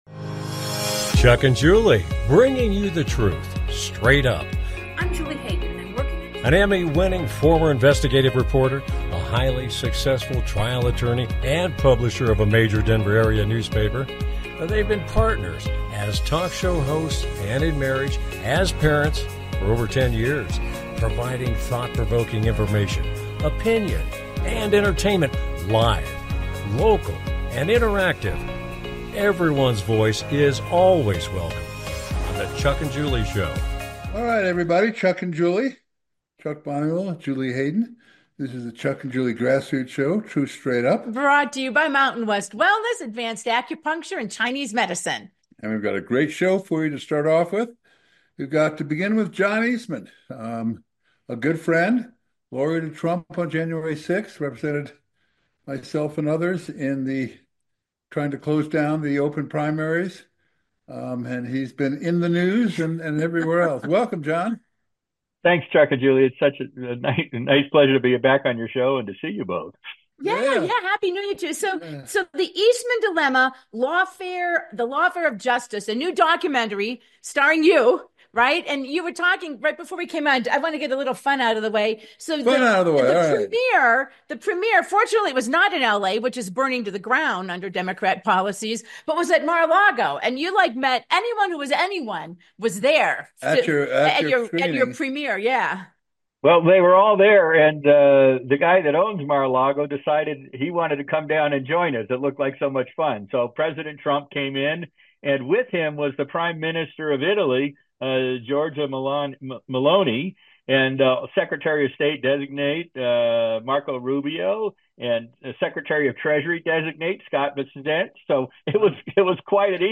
With guest, Dr. John Eastman Ph.D, professor and former lawyer - Dr. John Eastmans new documentary, The Eastman Dilemma